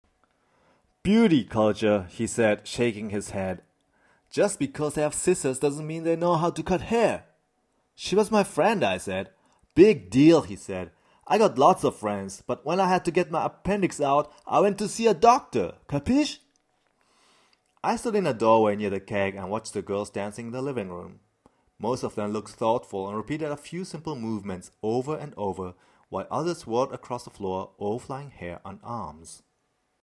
Sprecher japanisch, Profisprecher, für Werbung und Industrie
Kein Dialekt
Sprechprobe: Sonstiges (Muttersprache):